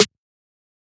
MZ Snare [Metro Generic Lo].wav